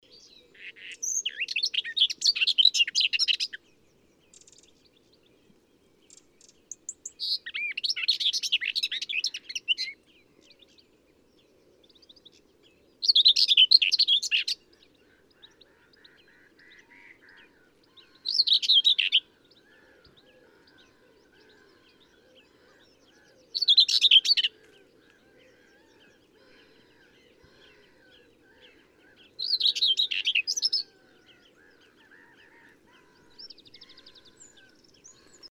PFR07297, 120710, Common Whitethroat Sylvia communis, song, Stiffkey, UK